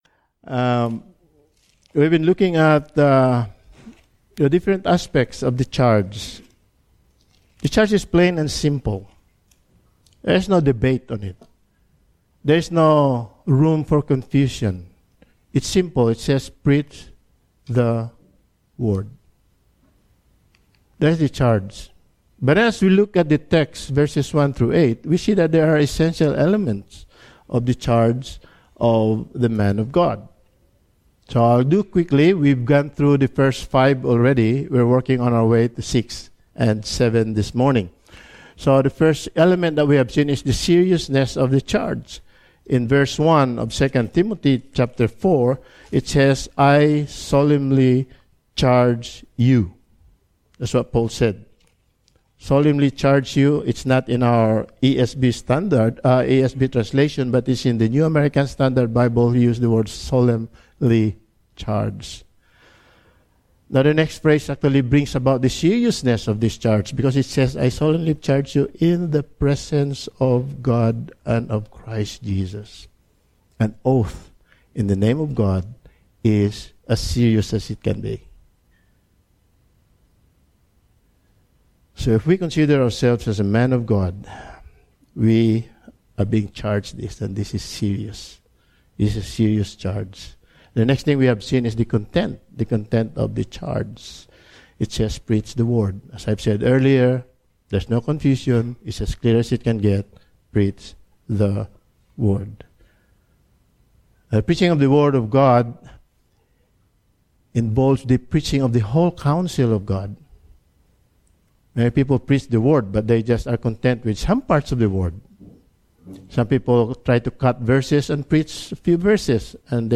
Passage: 2 Timothy 4:6-8 Service Type: Sunday Morning